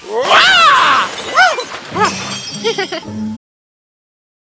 65 - INT. WATTO'S JUNK SHOP - DAY
JAR JAR pulls a part out of a stack of parts to inspect it, and they all come tumbling down. He struggles to catch them, only to knock more down.